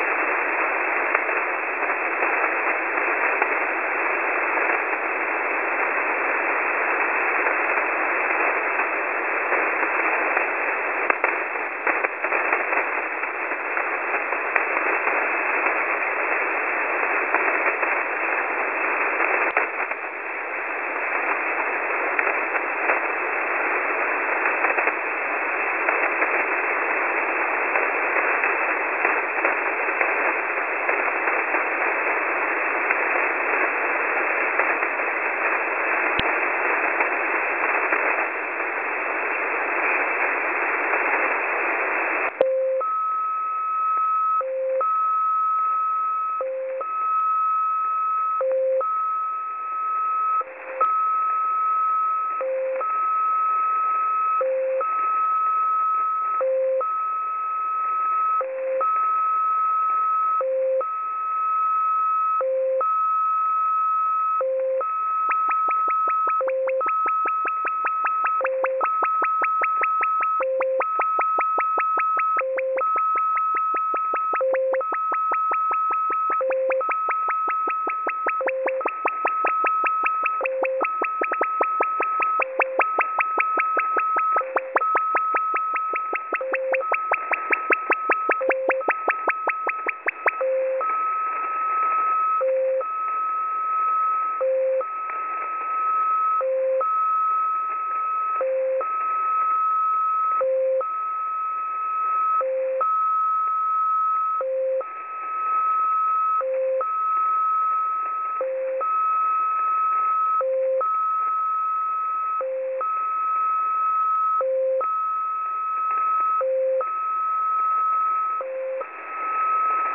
Time (UTC): 1730 Mode: USB Frequency: 9938 Recording: websdr_recording_start_2016-05-10T17_49_24Z_9938.0kHz.wav Waterfall Image: XPA Decode 9938 KHz 1750 GMT 1850 BST 100506.png Date (mm/dd/yy): 051016